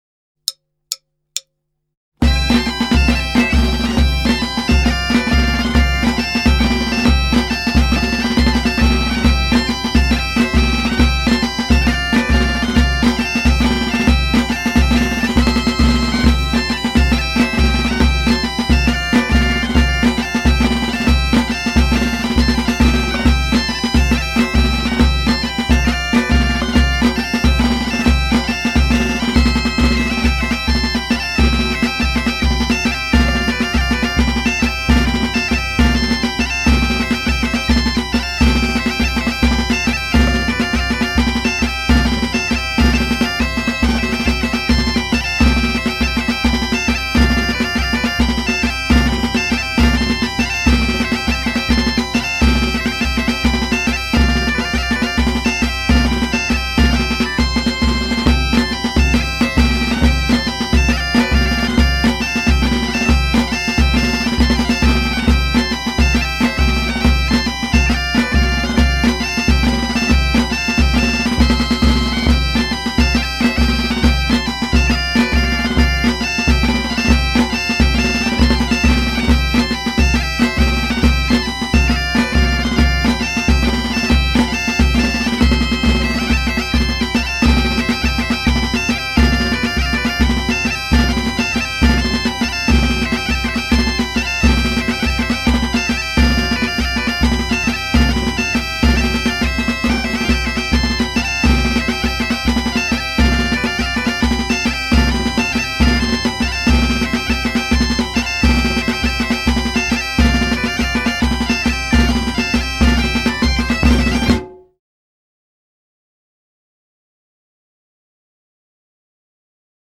arranxadas para cuarteto tradicional
dúas gaitas, tambor e bombo